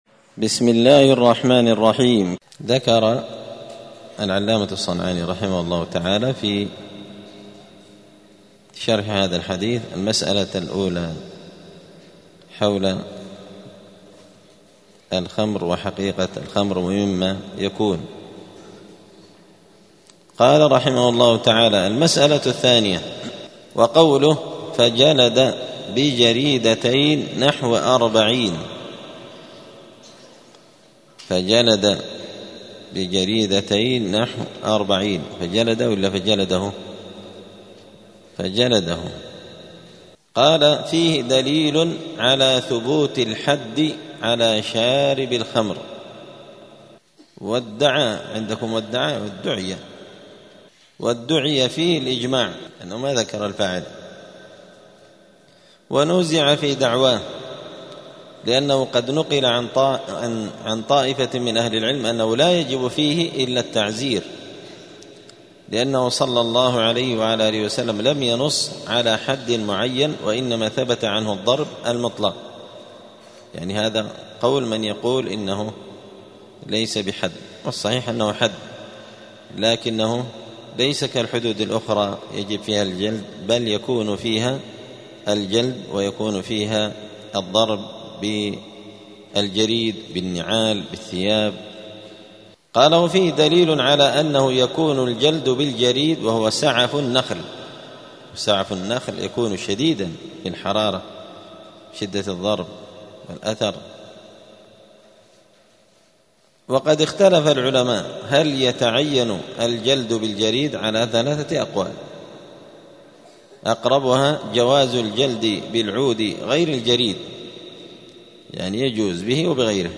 *الدرس الواحد والثلاثون (31) {باب حد الشارب مقدار حد شارب الخمر}*
دار الحديث السلفية بمسجد الفرقان قشن المهرة اليمن